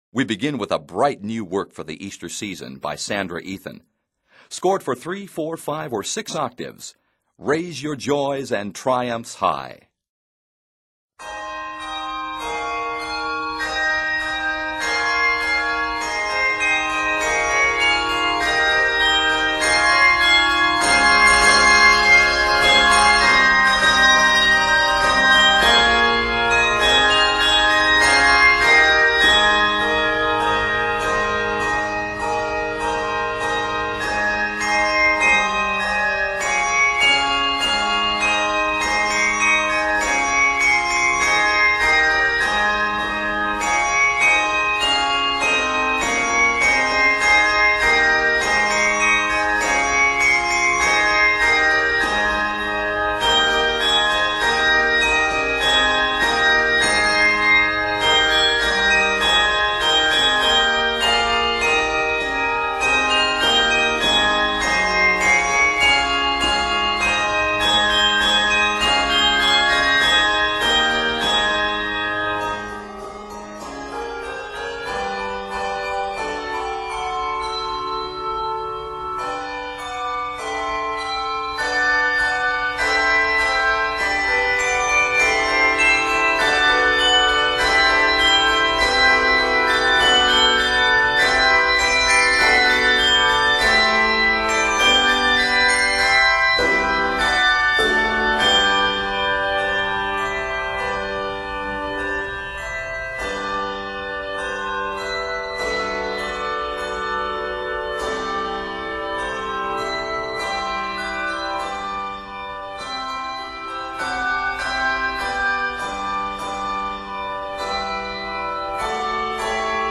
With bold harmonies, three triumphant tunes